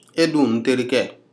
This is a dialogue of people speaking Nko as their primary language.